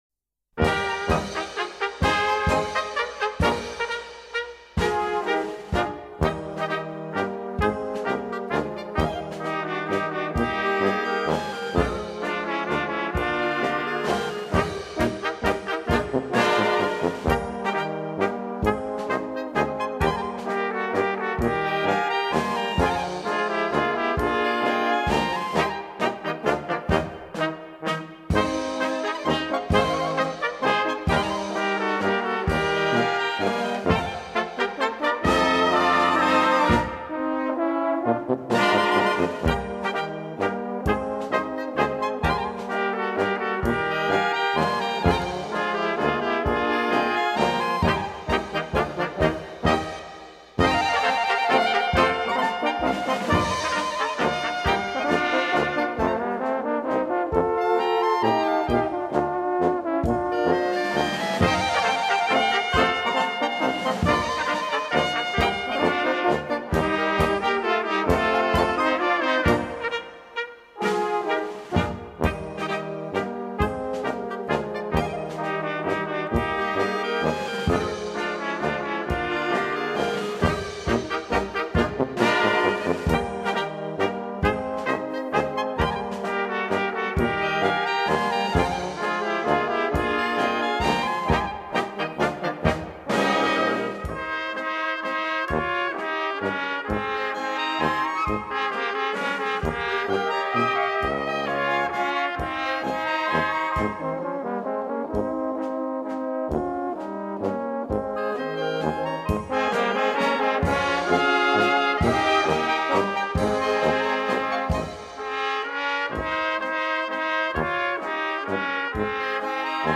langsamer Walzer, Orchestertitel